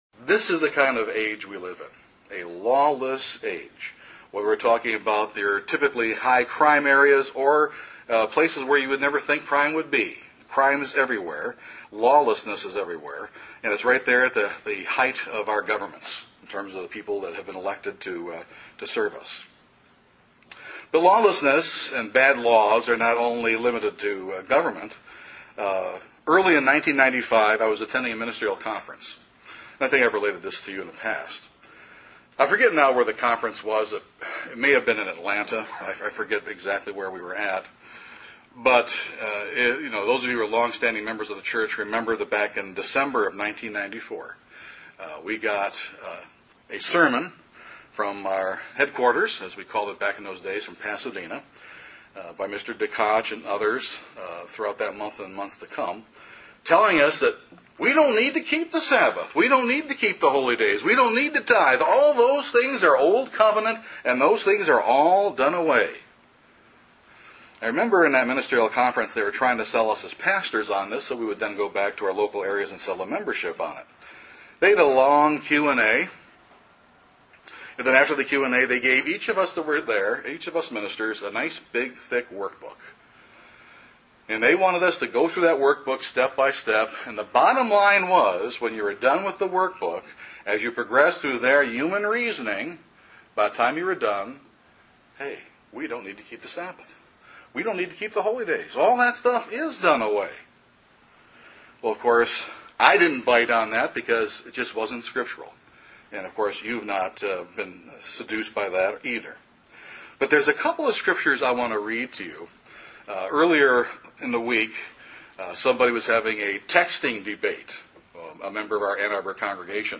This sermon drills down deeply into the reason for God giving us the Ten Commandments and His desire for mankind to live by a higher standard of righteousness.